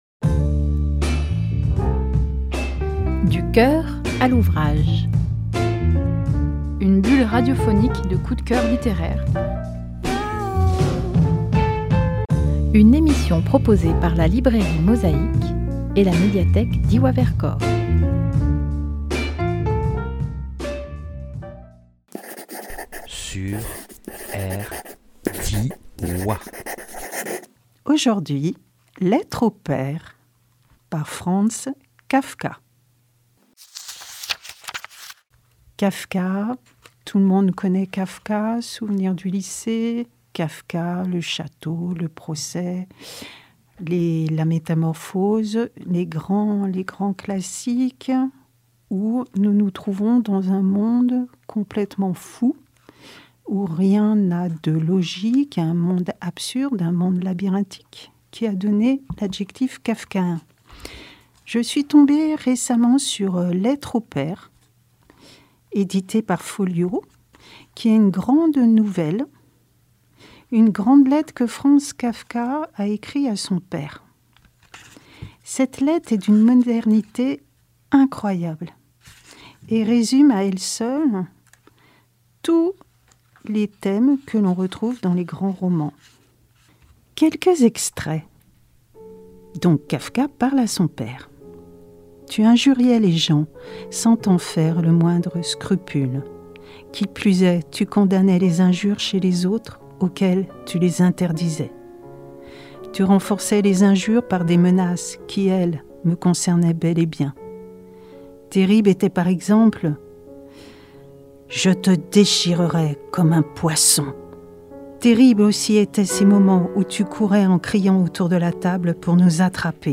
Studio Rdwa – avril 24